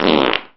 fart 2